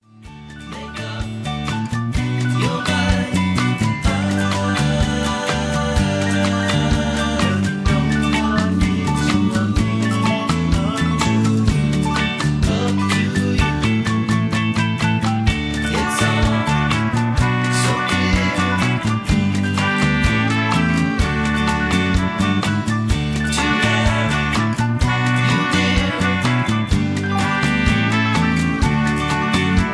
(Version-2, Key-D) Karaoke MP3 Backing Tracks
Just Plain & Simply "GREAT MUSIC" (No Lyrics).